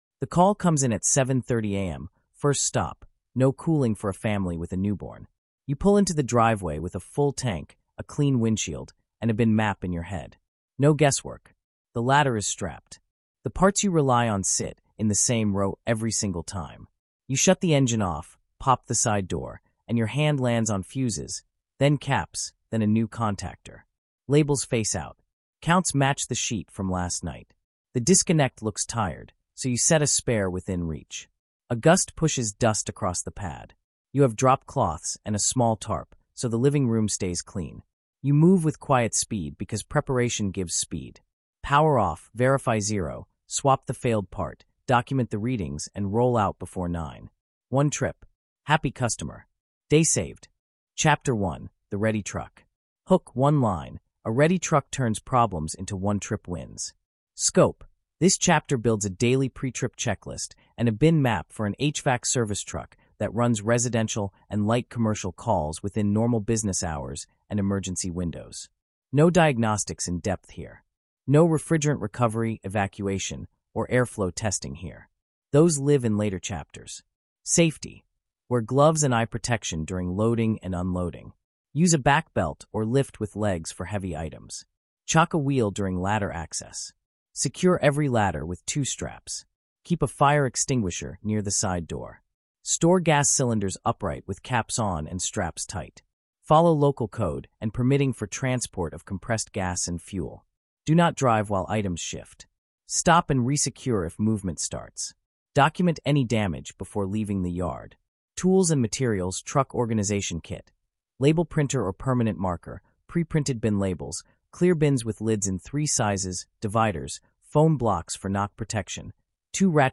Numbers, readings, and torque values appear in plain speech.